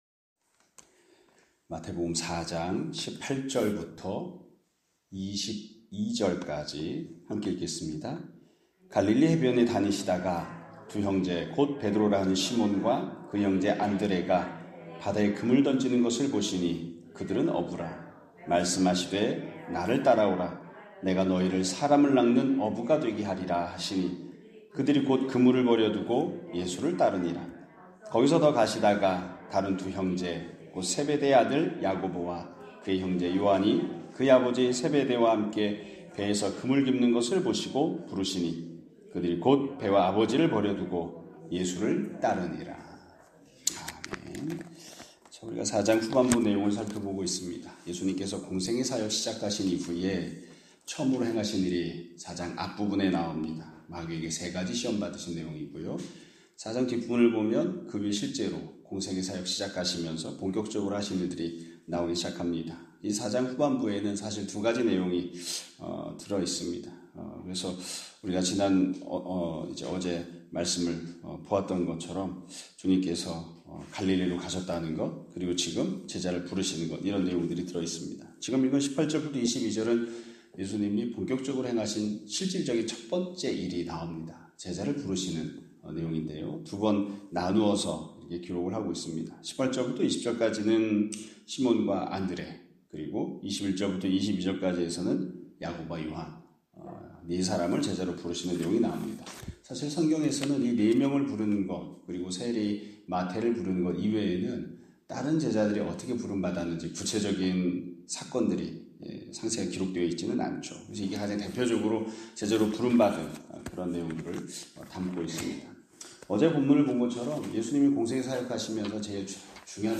2025년 5월 1일(목 요일) <아침예배> 설교입니다.